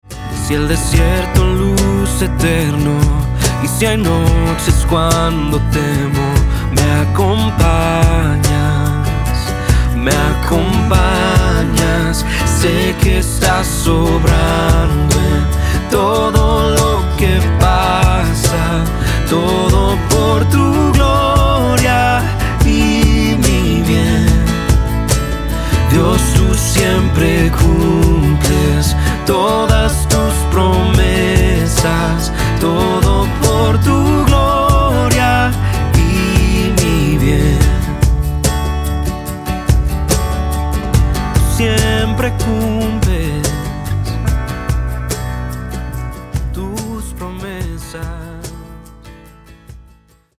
Acordes - D